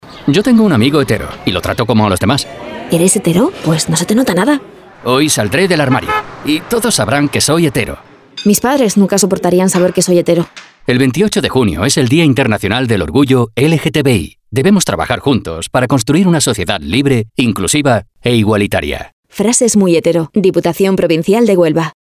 Cuña de radio